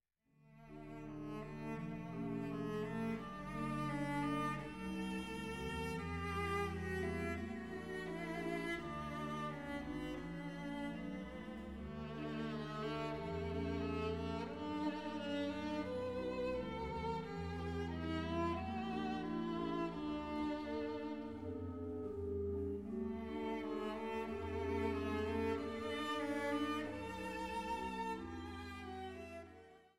für Violine, Violoncello & Orgel